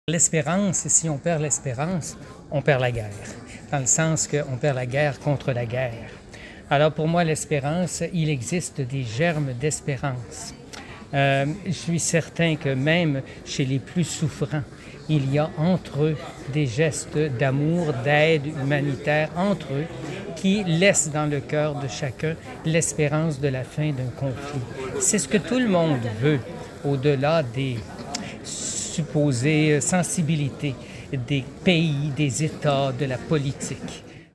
S. E. Raymond Poisson, évêque de Saint-Jérôme-Mont-Laurier depuis le 1er juin 2022, est président de la Conférence des évêques catholiques du Canada depuis septembre 2021.